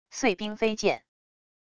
碎冰飞溅wav音频